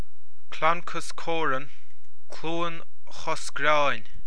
Pronunciation Audio File